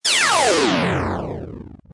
mech_detach.ogg